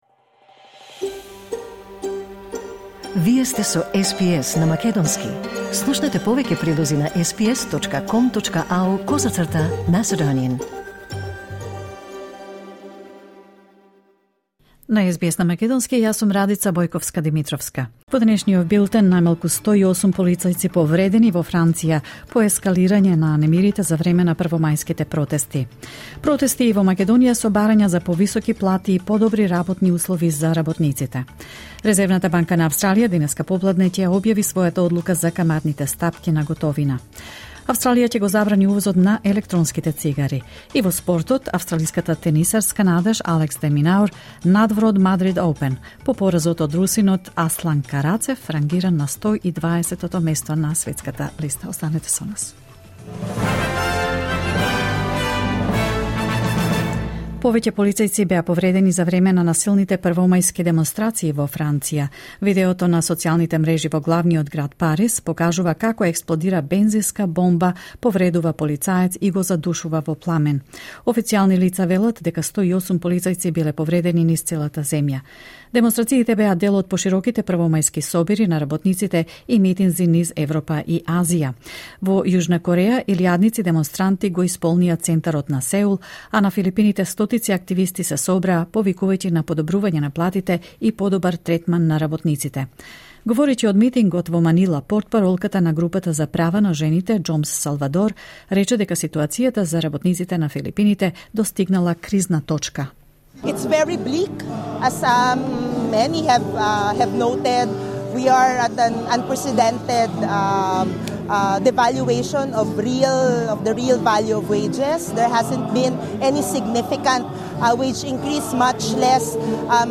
SBS News in Macedonian 2 May 2023